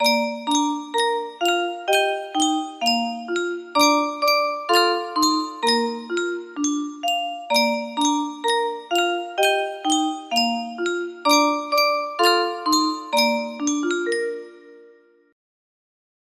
Yunsheng Music Box - Old MacDonald Had a Farm Y164 music box melody
Full range 60